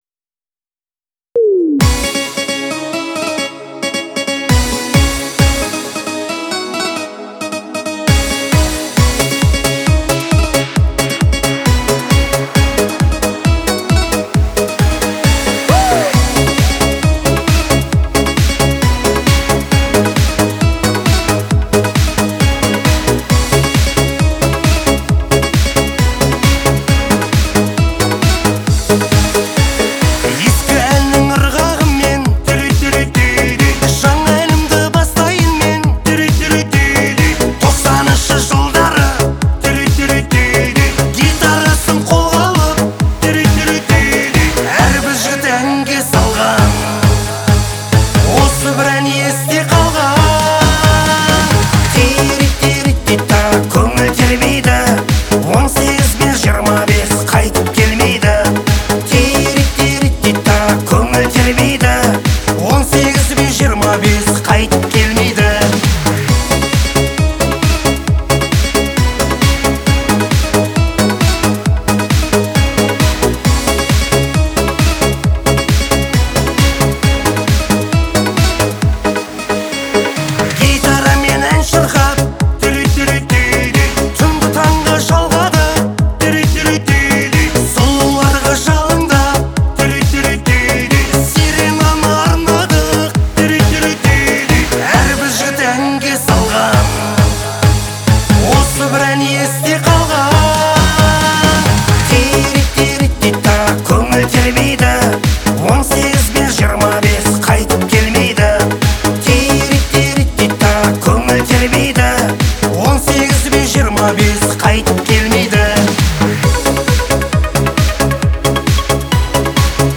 наполнено динамичными битами и мелодичными вокалами